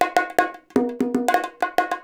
100 BONGO6.wav